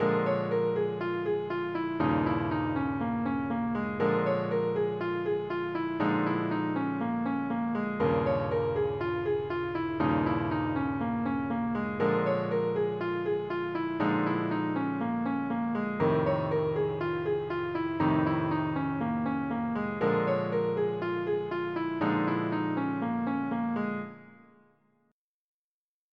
blues scale over blues changes
This example uses the blues scale.
This example is simply the same two-measure line repeated six times.